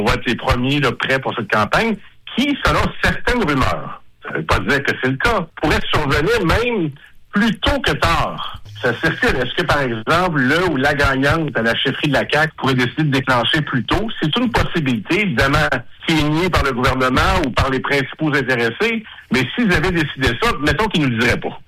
Comme le dit le député de Matapédia-Matane, Pascal Bérubé, la rumeur à cet effet a commencé à courir, au parlement :